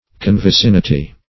Search Result for " convicinity" : The Collaborative International Dictionary of English v.0.48: Convicinity \Con`vi*cin"i*ty\, n.; pl.